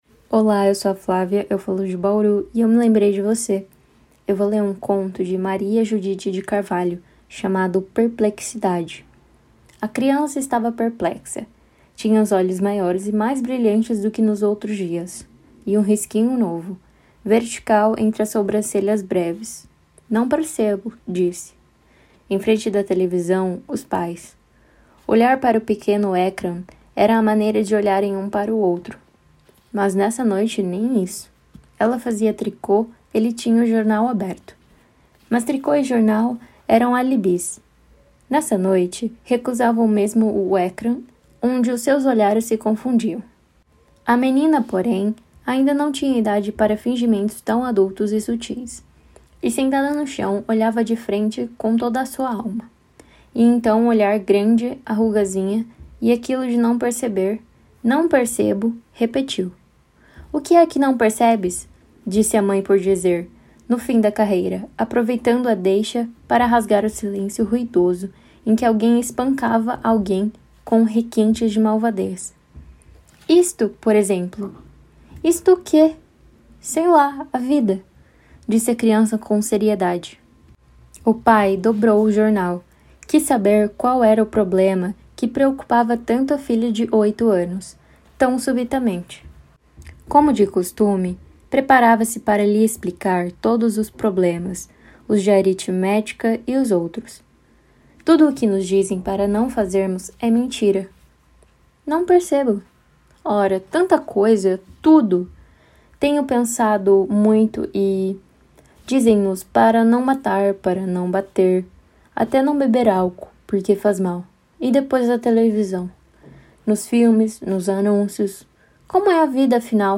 Conto Português